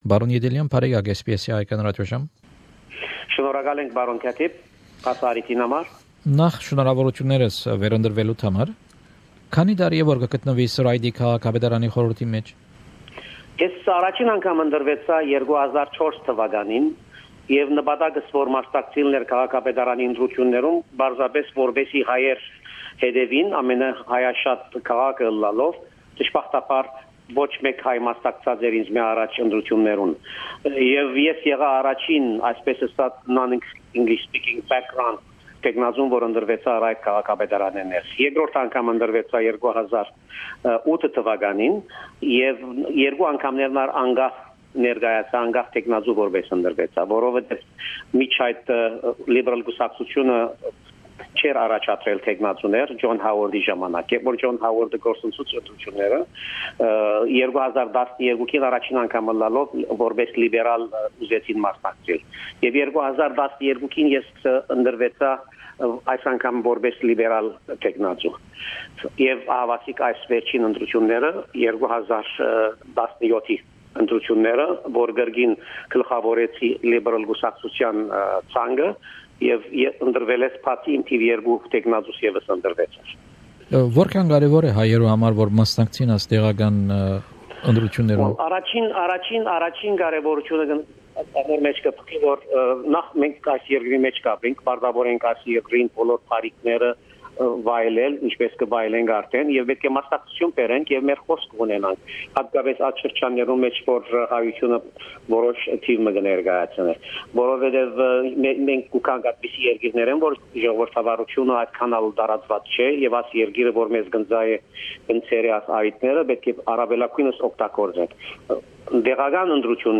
An interview (in Armenian) with City of Ryde Councillor, Mr Sarkis Yedelian OAM.